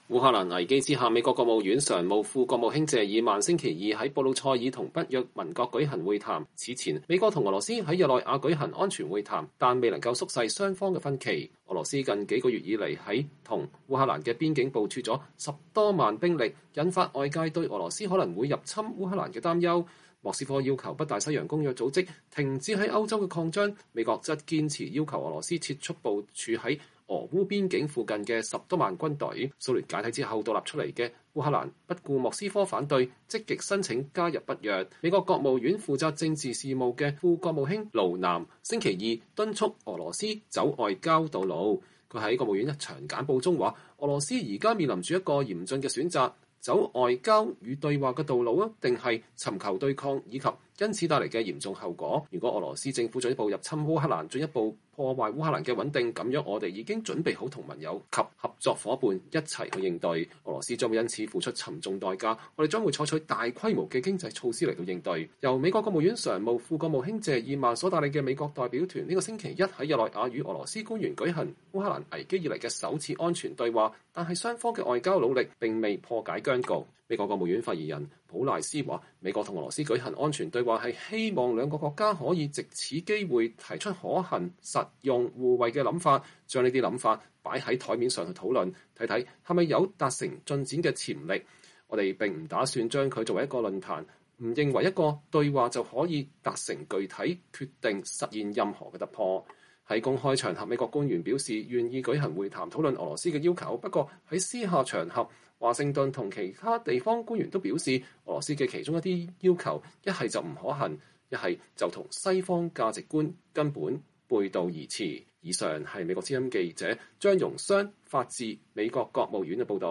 拜登總統與賀錦麗副總統1月11號在佐治亞州發表投票權演說，呼籲國會儘速通過兩項與投票權相關法案。